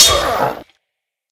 SH_hit2.ogg